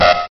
Sound Buttons: Sound Buttons View : Black Mesa Alarm (Fast)